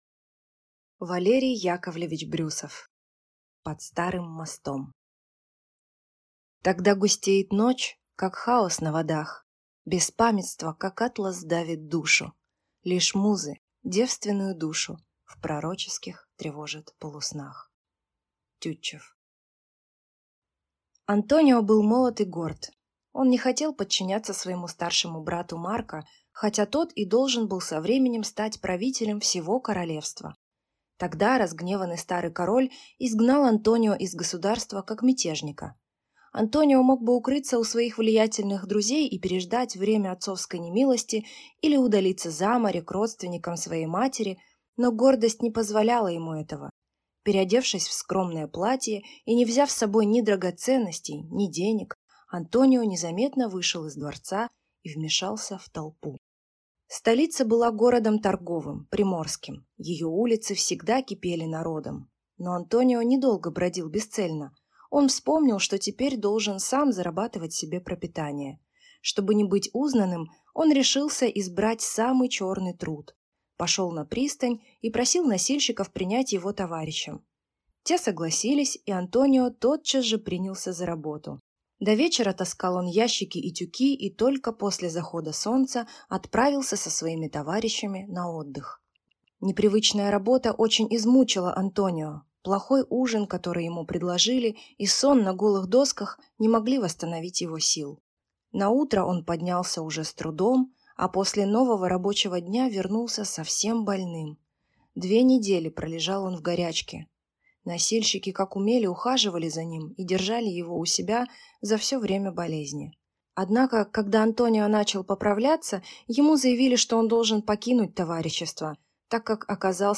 Аудиокнига Под Старым мостом | Библиотека аудиокниг
Прослушать и бесплатно скачать фрагмент аудиокниги